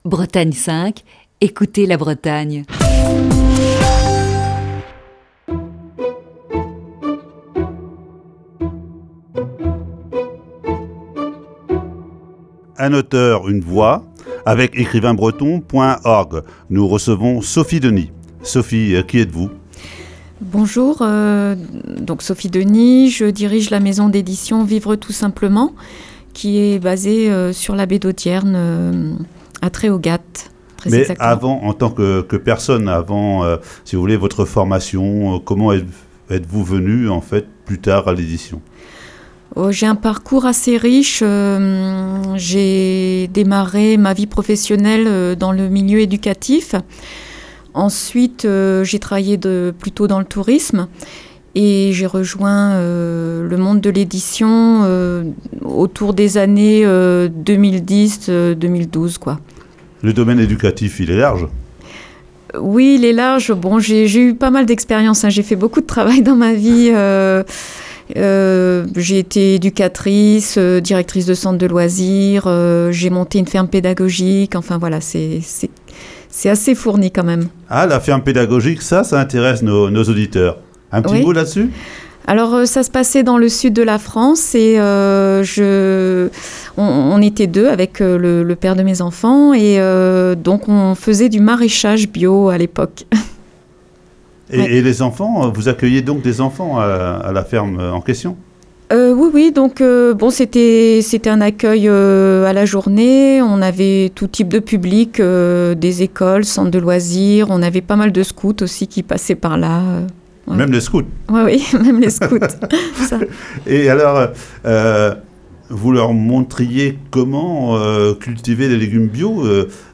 1ère partie (Rediffusion)